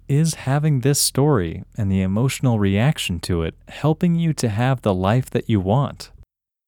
OUT – English Male 21